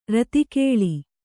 ♪ rati kēḷi